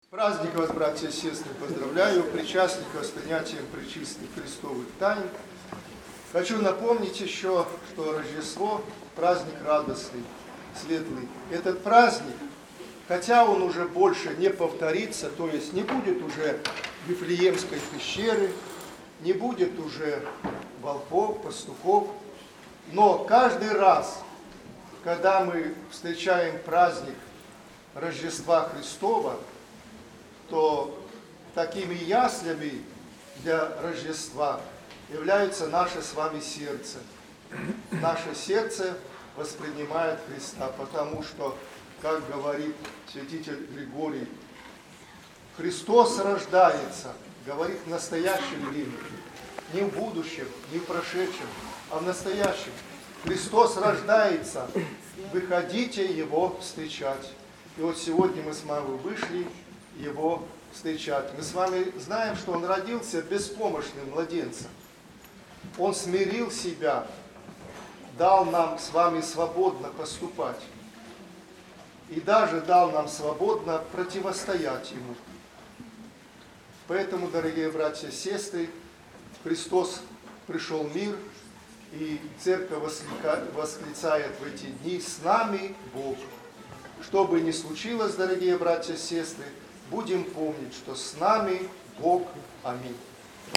в праздник Рождества Христова после ночной литургии